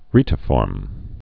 (rētə-fôrm, rĕtə-)